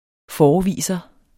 Udtale [ ˈfɒːɒˌviˀsʌ ]